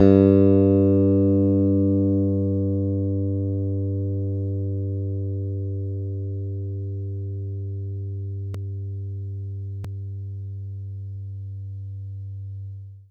interactive-fretboard / samples / bass-electric / G2.wav